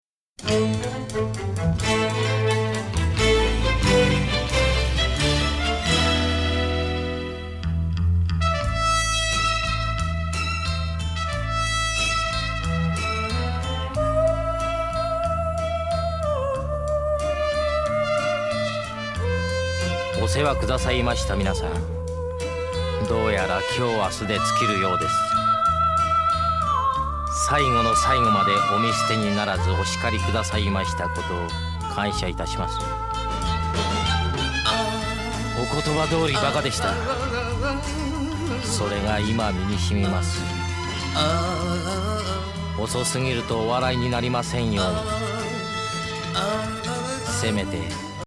男臭すぎ昭和歌謡男体盛り!?